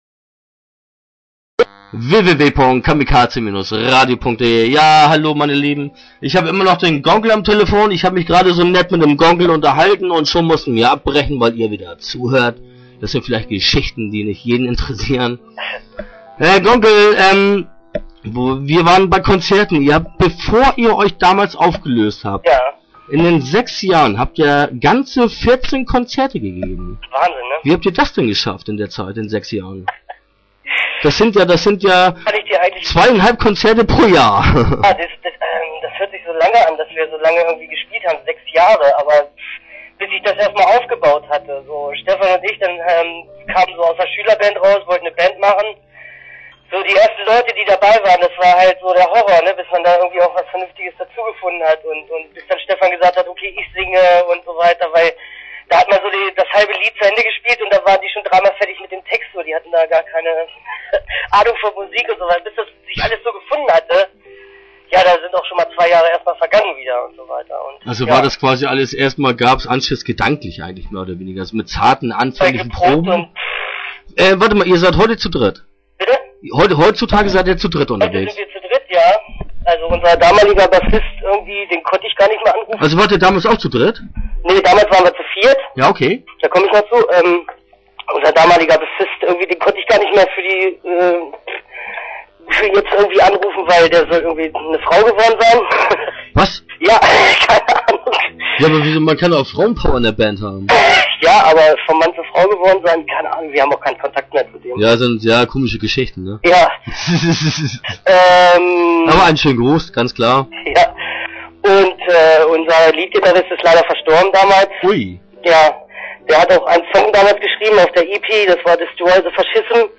Interview Teil 1 (5:20)